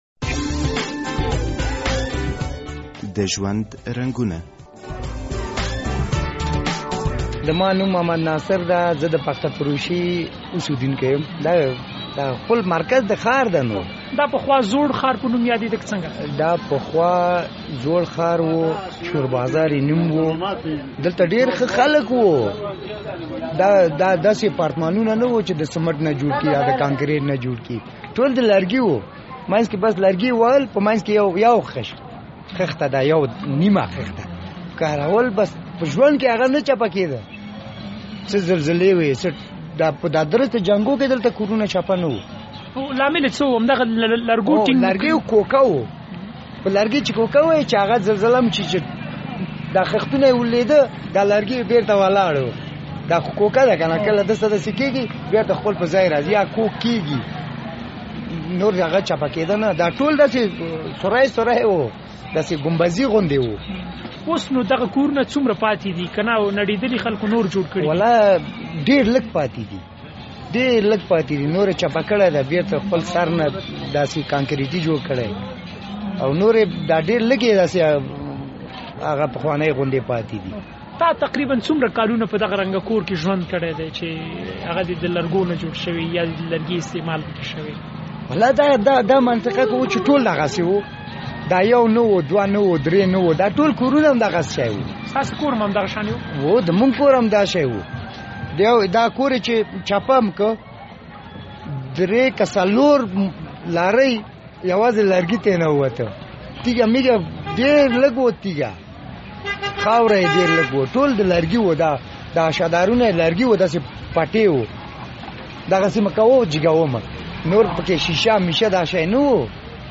مرکه کړې ده